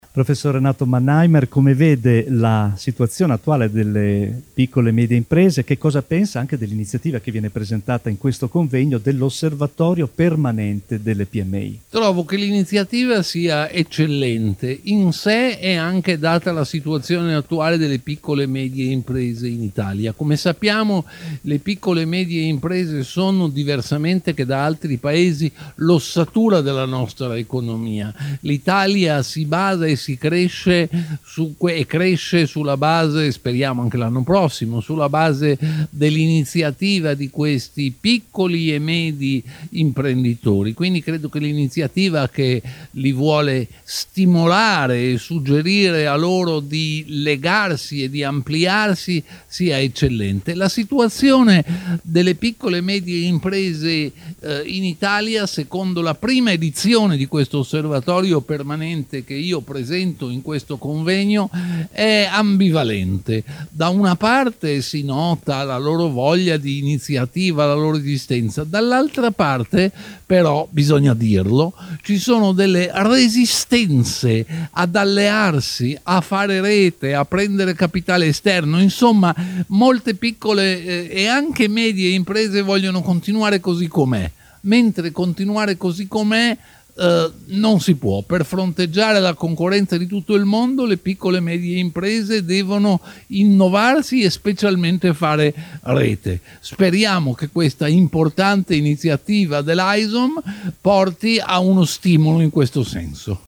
Lieti di essere main sponsor di questo importante evento, “Strategia per il rinascimento delle PMI”, organizzato AICIM e AISOM presso il Grand Hotel Magestic già Baglioni di Bologna.
Intervista a : Renato Mannheimer.